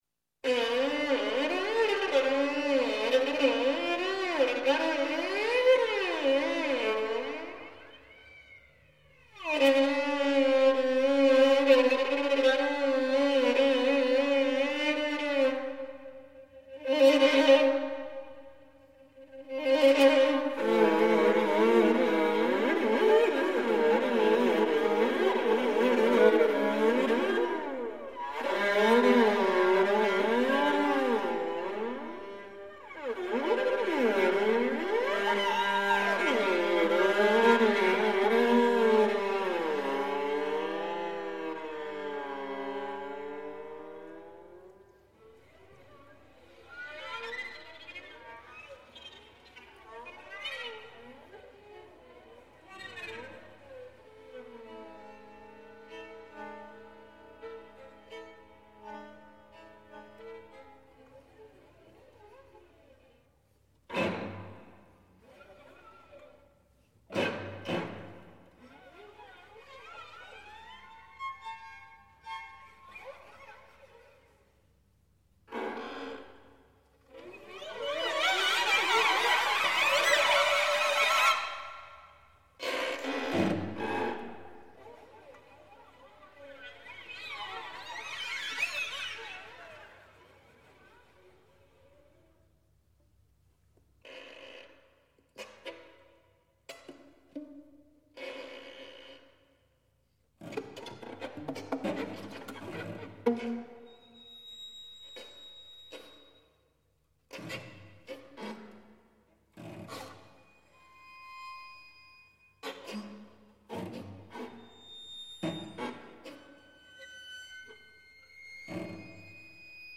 for string quartet